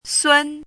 怎么读
sūn